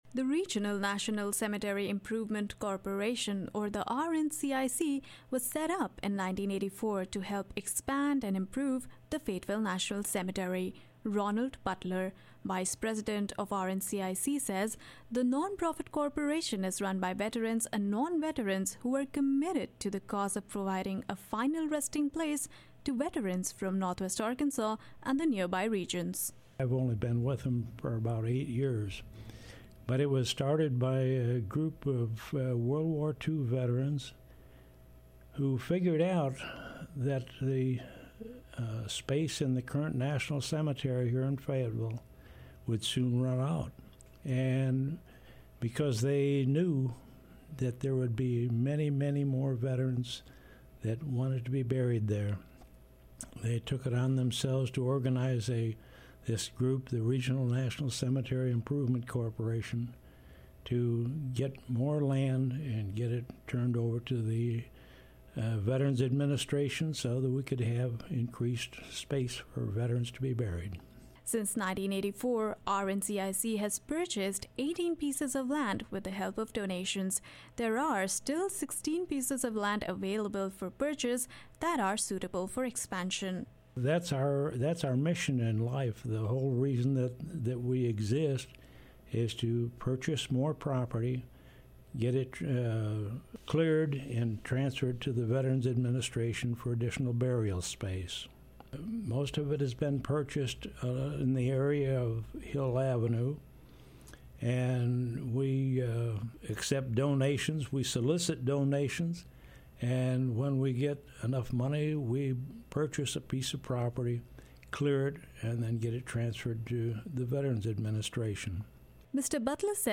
A conversation with one man working to keep the Fayetteville National Cemetery open and growing.